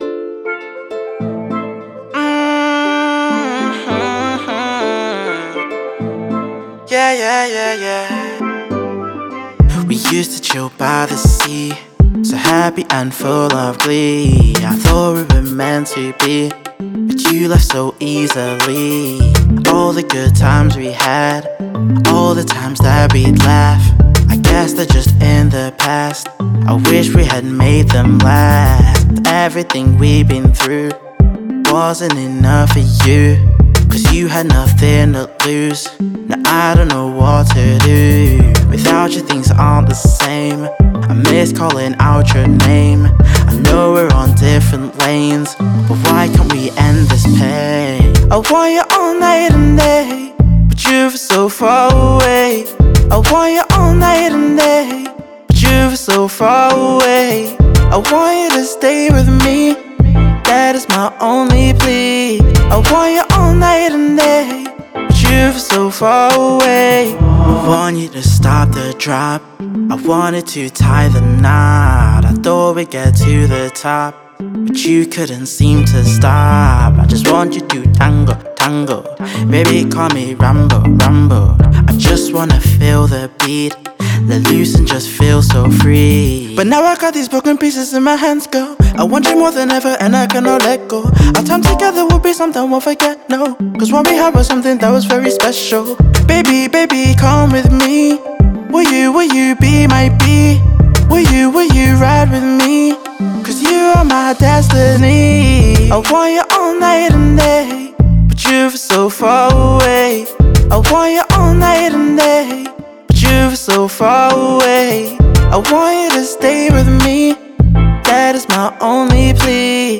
my main genre overall I’d say is pop